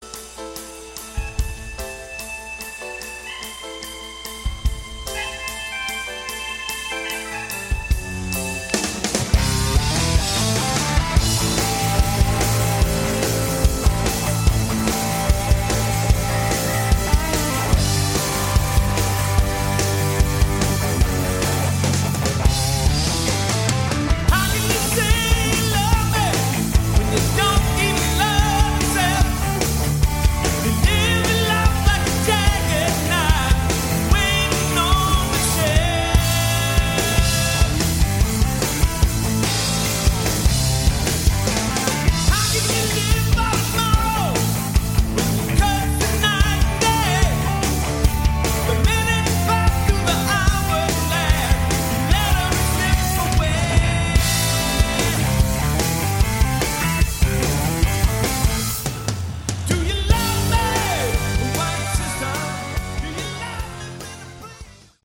Category: AOR
live